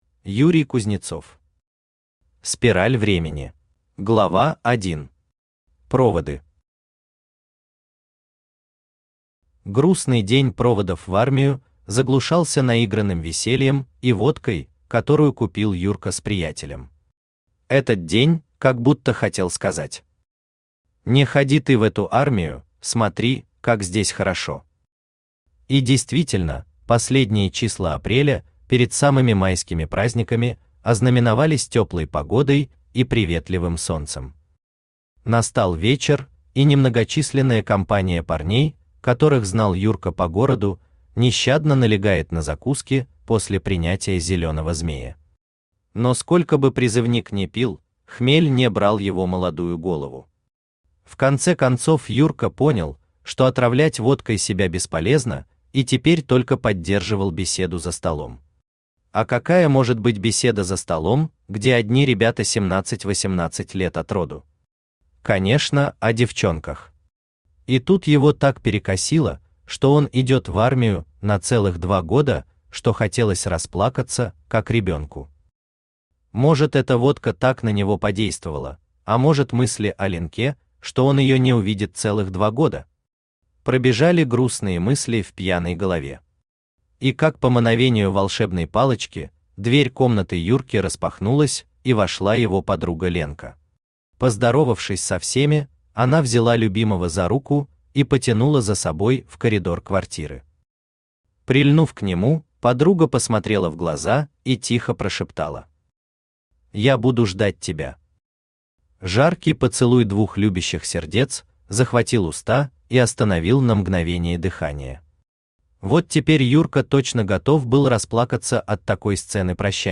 Аудиокнига Спираль времени | Библиотека аудиокниг
Aудиокнига Спираль времени Автор Юрий Юрьевич Кузнецов Читает аудиокнигу Авточтец ЛитРес.